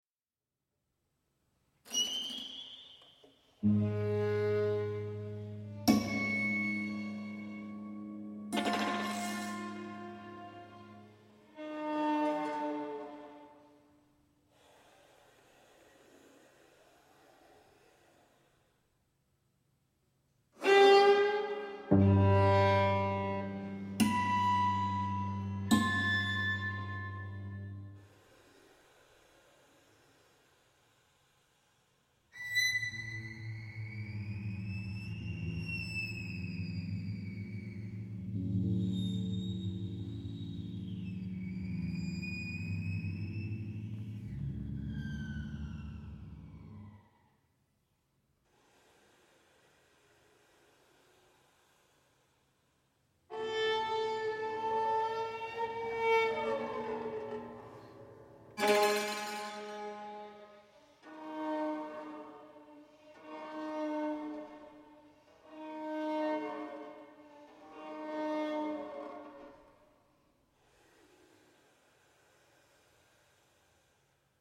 for violin and cimbalom